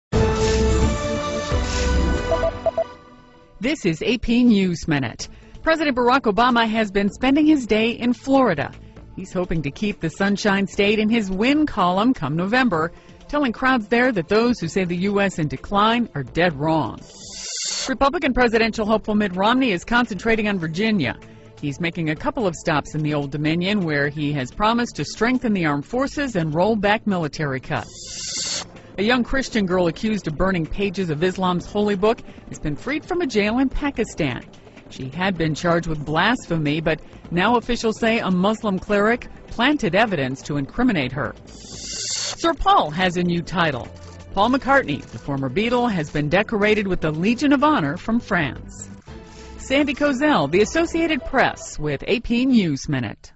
在线英语听力室美联社新闻一分钟 AP 2012-09-11的听力文件下载,美联社新闻一分钟2012,英语听力,英语新闻,英语MP3 由美联社编辑的一分钟国际电视新闻，报道每天发生的重大国际事件。电视新闻片长一分钟，一般包括五个小段，简明扼要，语言规范，便于大家快速了解世界大事。